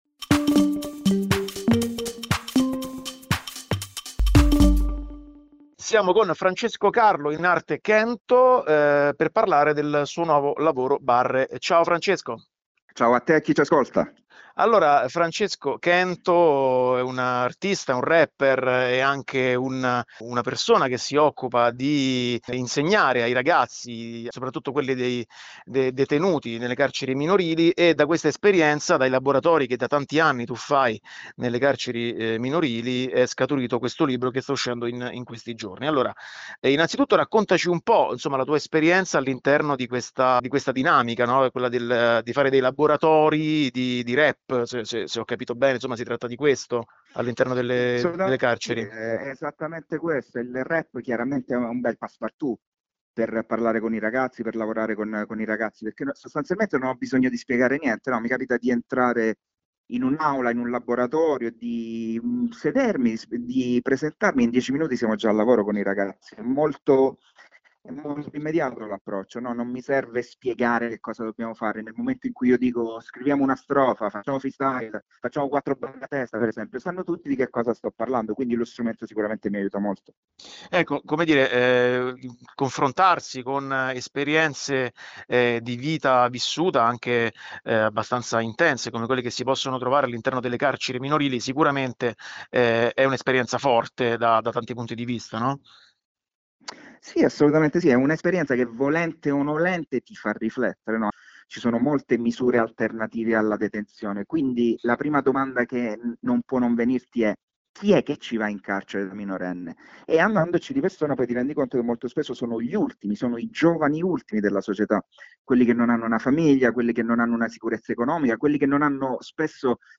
Archivi categoria: Intervista